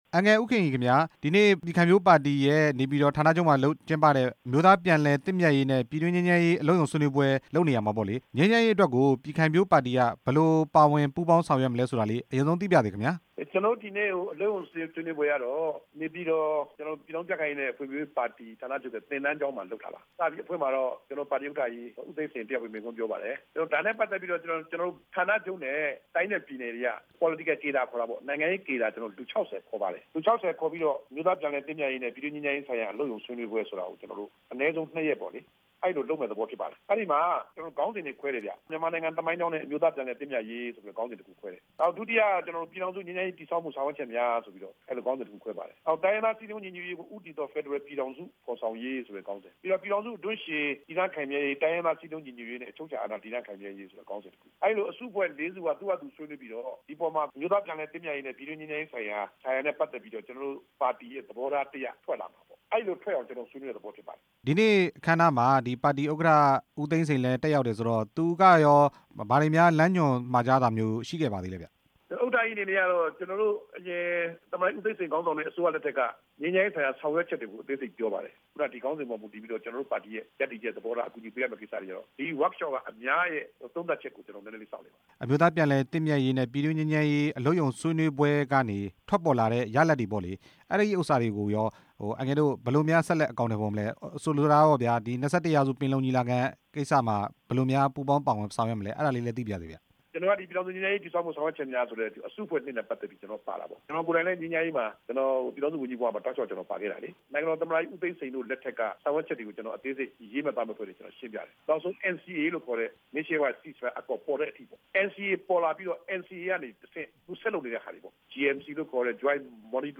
ကြံ့ခိုင်ဖံ့ွဖြိုးရေးပါတီရဲ့ အလုပ်ရုံဆွေးနွေးပွဲအကြောင်း ဦးခင်ရီ ကို မေးမြန်းချက်